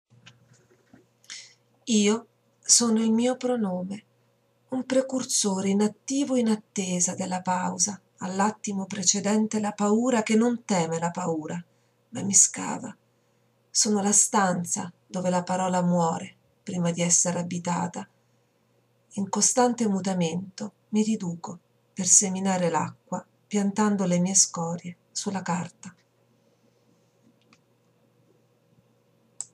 testo e voce